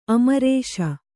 ♪ amarēśa